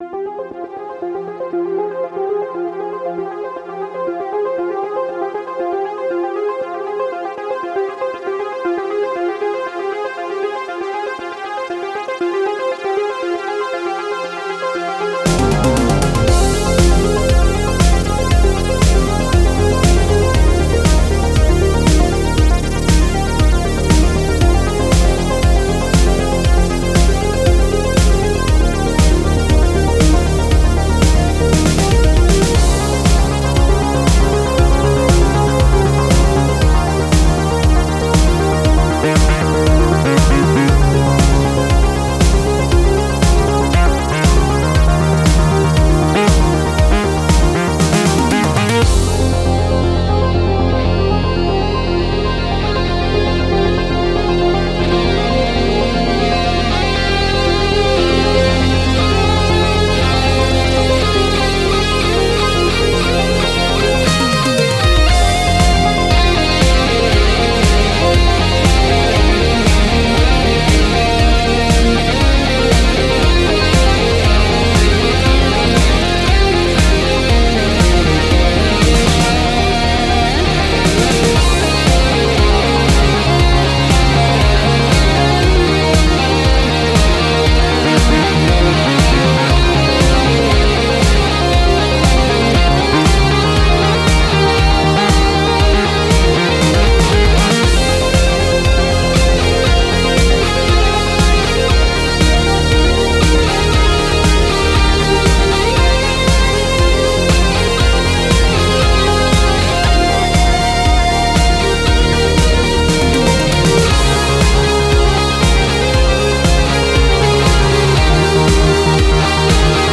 Genre: Spacesynth.